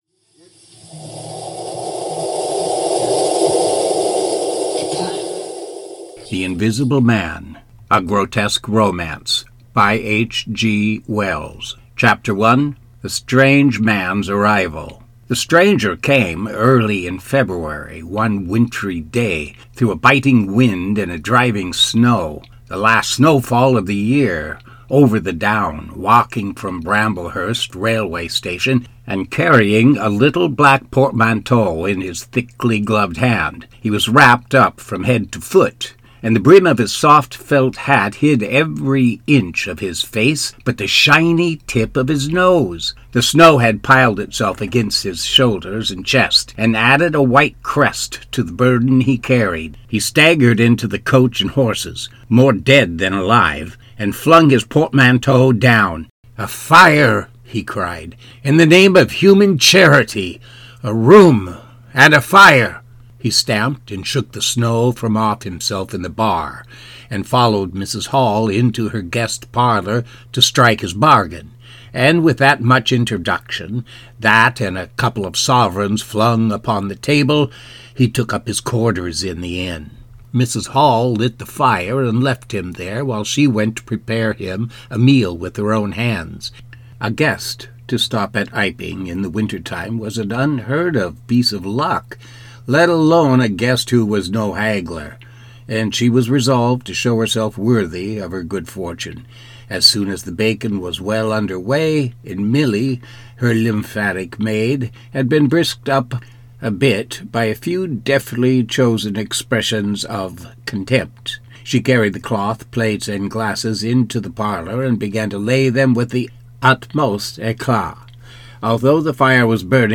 AudioBook length: 5 hours, 21 minutes Best Price at Nook – Barnes & Noble: $7.95 Click to Purchase!